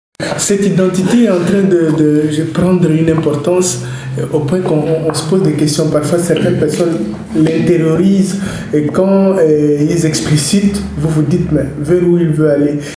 uitspraak